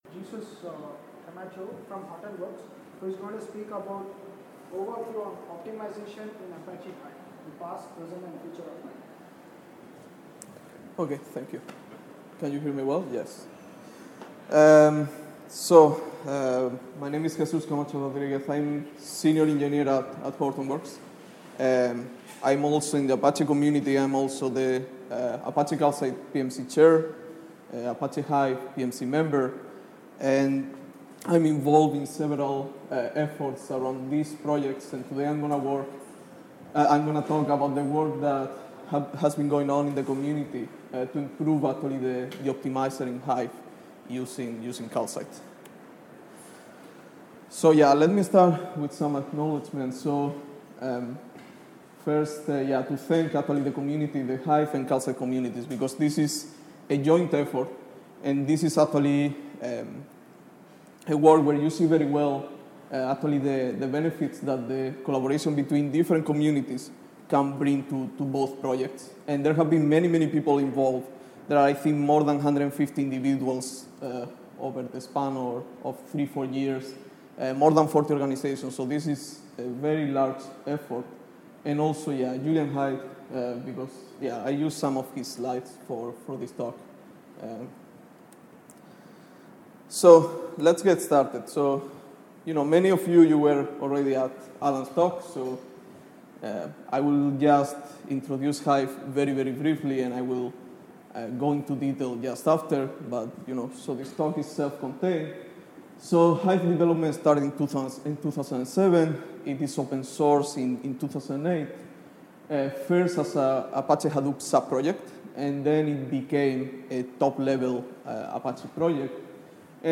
Apache Big Data Seville 2016